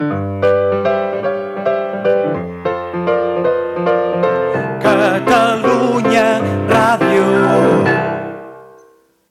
Maquetes, intrerpretades al piano